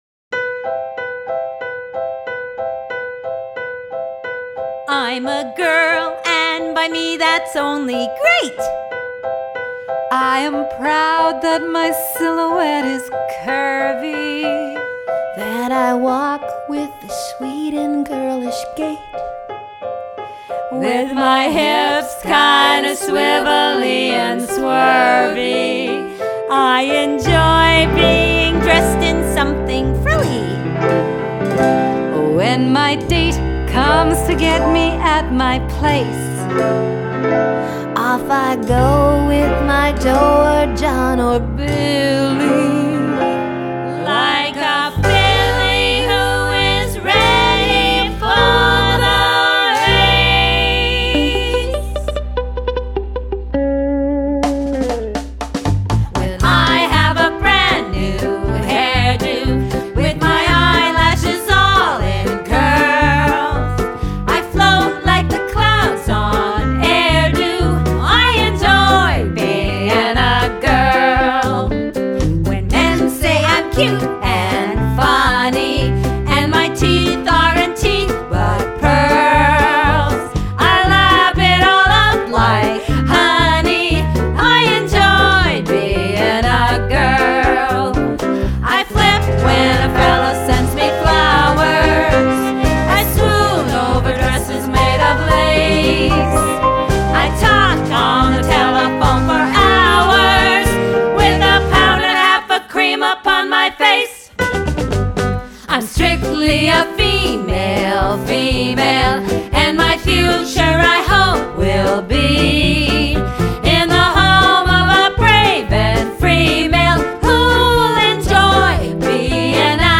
vocals, fiddle
vocals, bass, guitar, and mandolin
vocals, guitar
vocals, bass, mandolin
drums
piano, vocals…